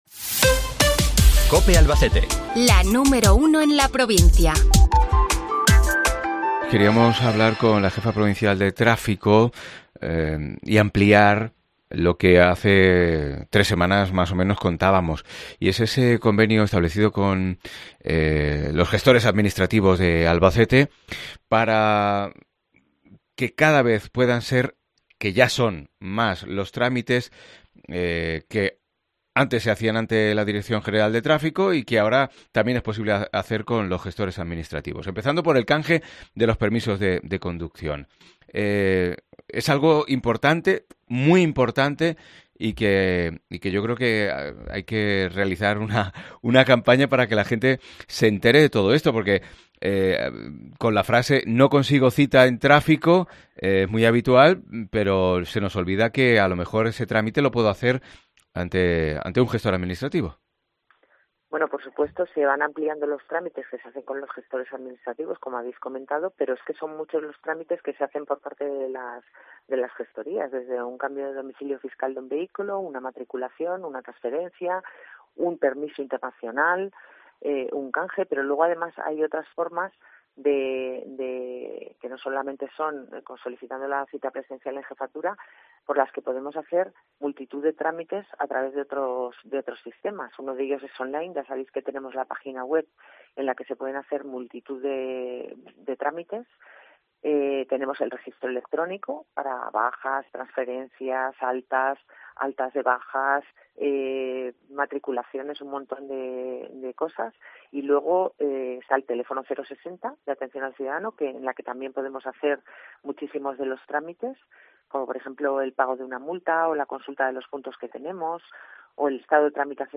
REPORTAJE COPE
Cruz Hernando, jefa provincial de Tráfico de Albacete